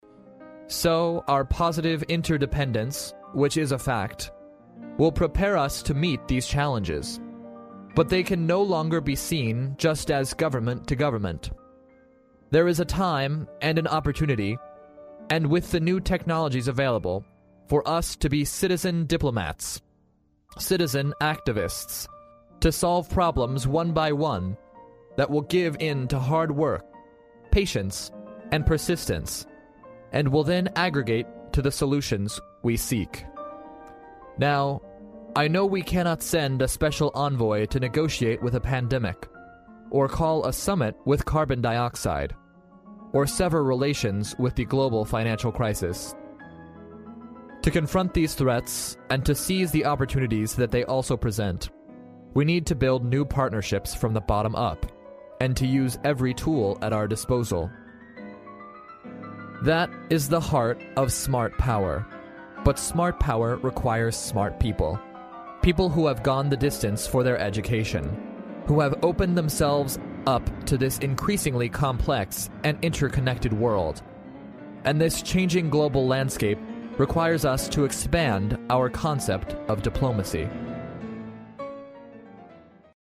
历史英雄名人演讲 第74期:希拉里在纽约大学毕业典礼的演讲(2) 听力文件下载—在线英语听力室